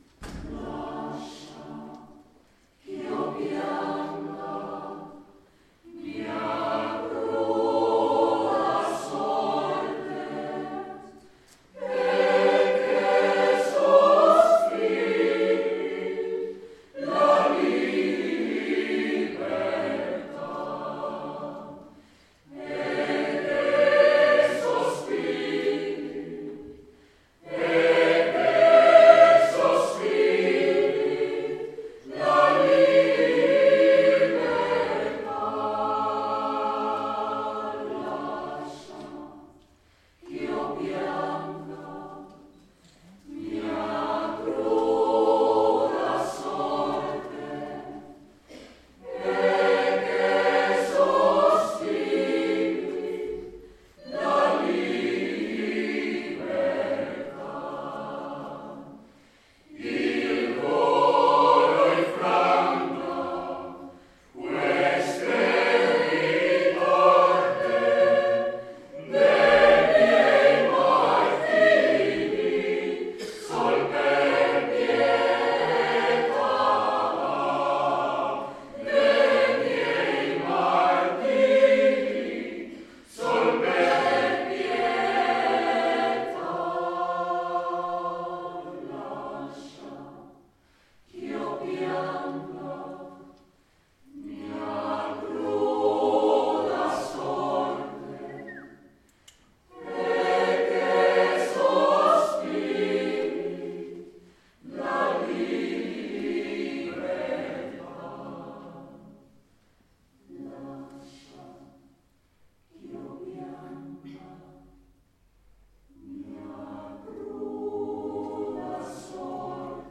Extraits audios du concert du dimanche 12 mai 2013
Chorale HARMONIA de Valladolid Lascia chi'o pianga  G.F. Haendel Harmonia Valladolid lascia chio pianga
17h00 : concert des trois chorales à l'Eglise Saint Bertrand du Mans :